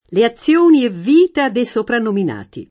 sopranominato [Sopranomin#to] o sopra nominato [id.] agg.
le aZZL1ni e vv&ta de Sopranomin#ti] (Machiavelli) — raro in questo senso soprannominato